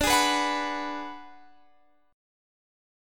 Listen to D6b5 strummed